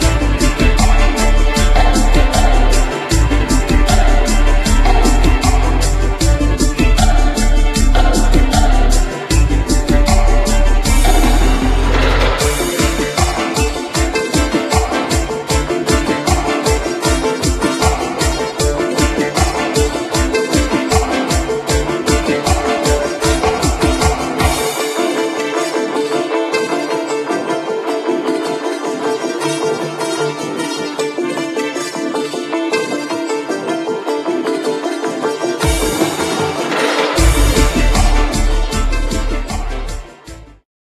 radosne, żywiołowe, roztańczone.